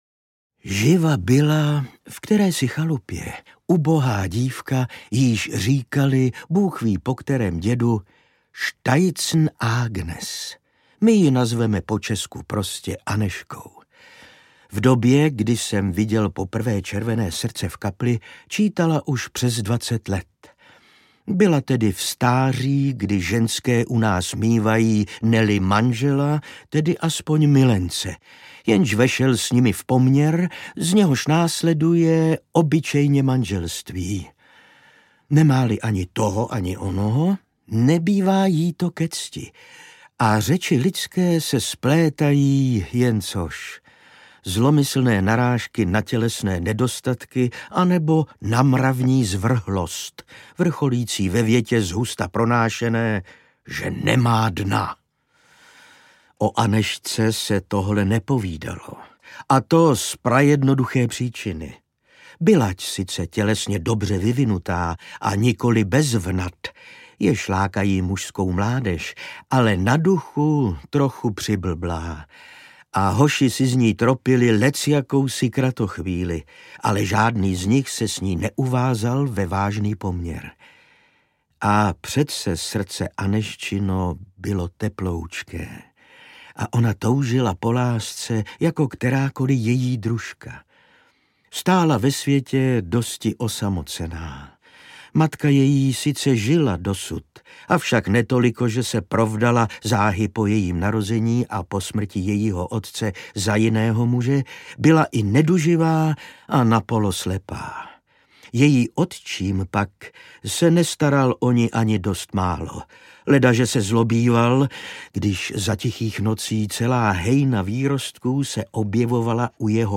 Ukázka z knihy
Čte Ivan Řezáč.
Vyrobilo studio Soundguru.